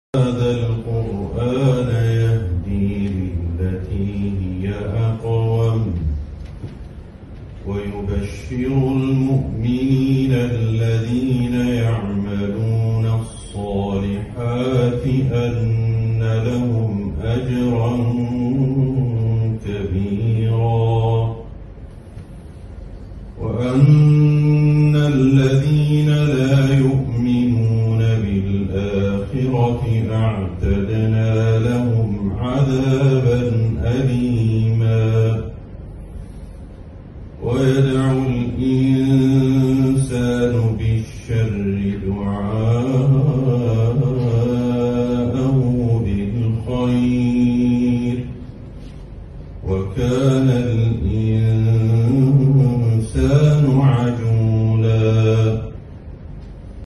مقتطف لتلاوة جميلة من سورة الإسراء من اندونيسيا